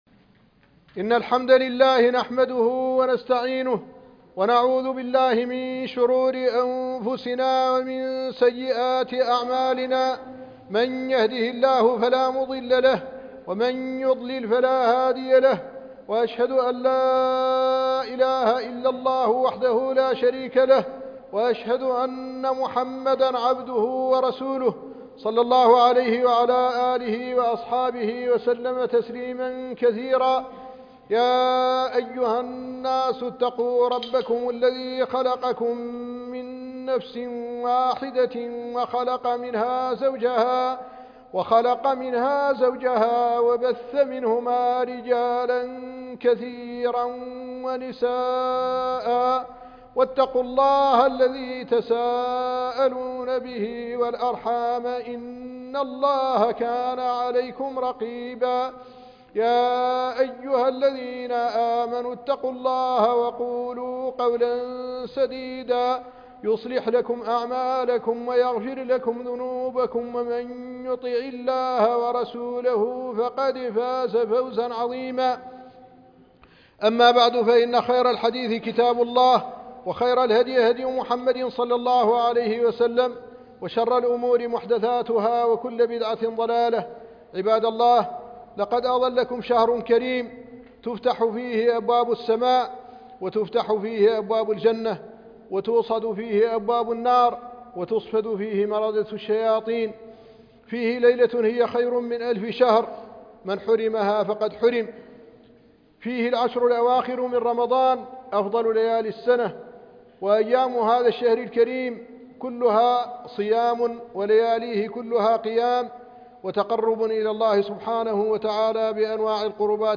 كيف تستعد لشهر رمضان ؟ خطبة الجمعة - الشيخ محمد الحسن ولد الددو الشنقيطي